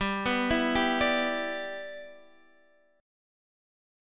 G6 Chord
Listen to G6 strummed